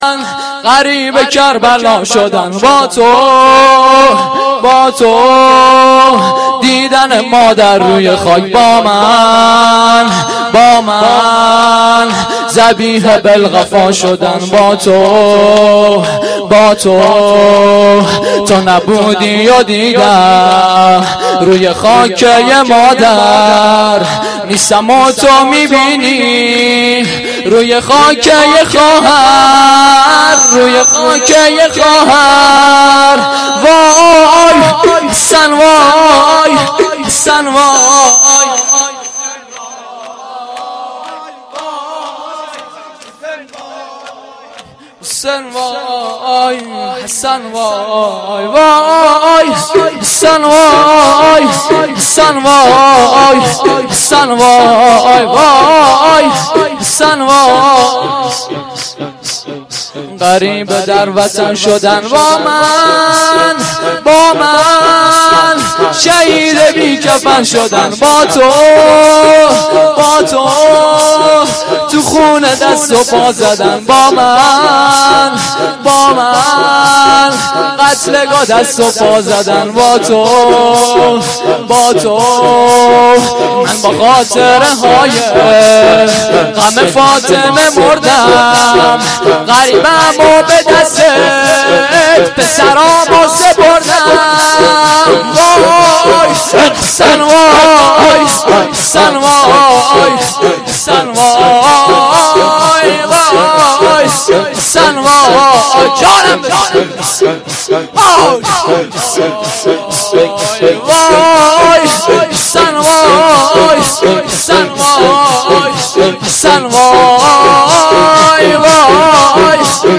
جلسه هفتگی(93.03.07) متاسفانه مرورگر شما، قابیلت پخش فایل های صوتی تصویری را در قالب HTML5 دارا نمی باشد.
روضه العباس